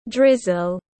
Mưa phùn tiếng anh gọi là drizzle, phiên âm tiếng anh đọc là /ˈdrɪz.əl/.